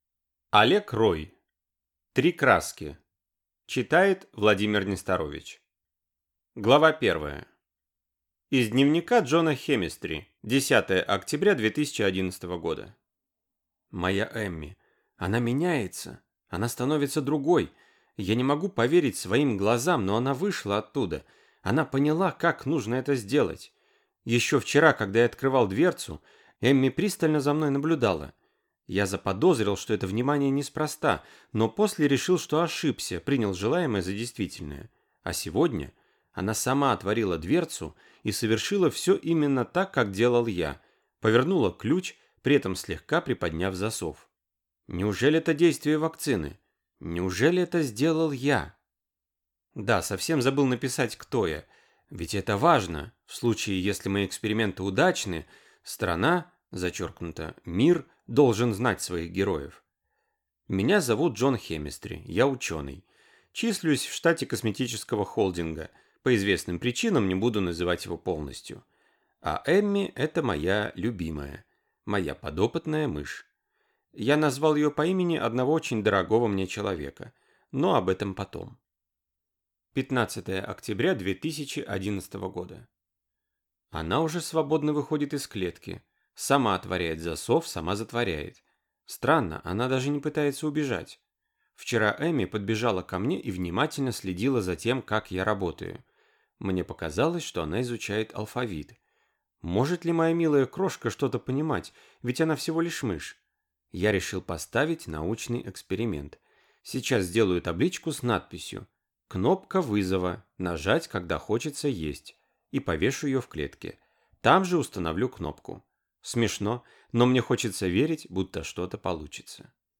Aудиокнига Три краски